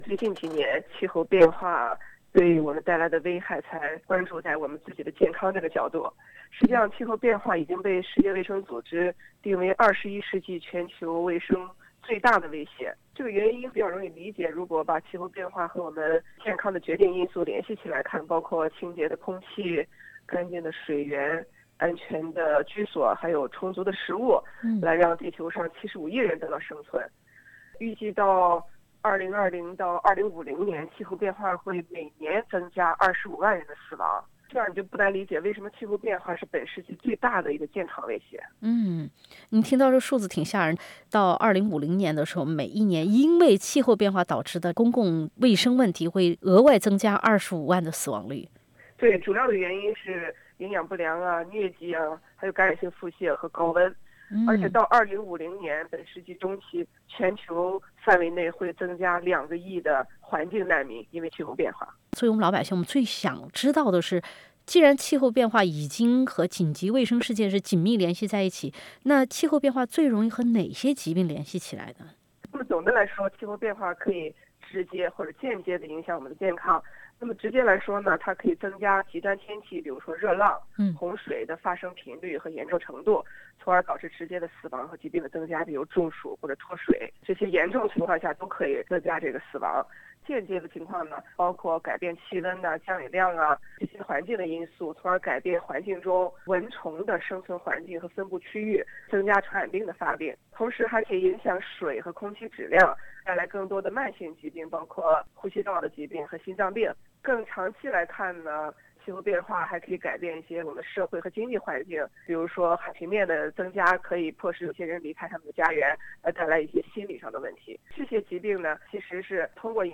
SBS Mandarin View Podcast Series